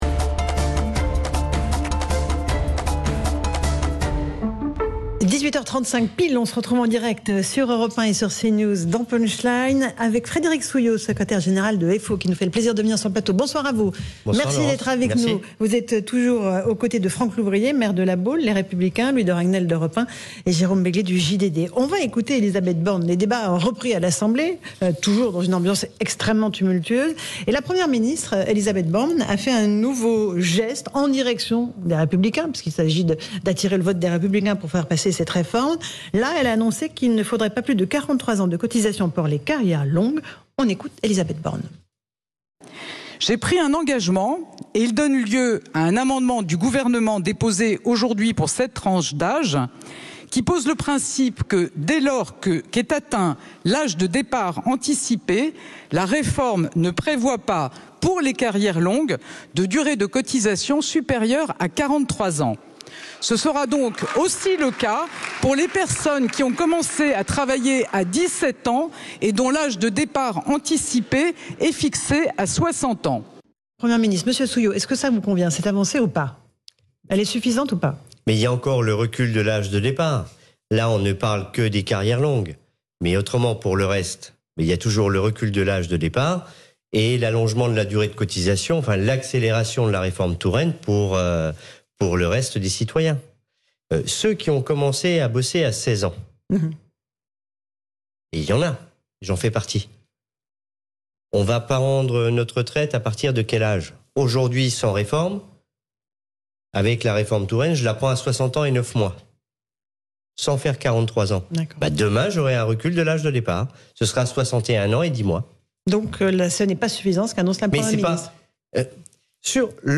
Retraites : Frédéric Souillot, Secrétaire général de FO, était l'invité de Laurence Ferrari | Force Ouvrière
Frédéric Souillot, Secrétaire général de Force Ouvrière, était l'invité de l'émission de Laurence Ferrari, « Punchline » sur CNews le mardi 14 février 2023.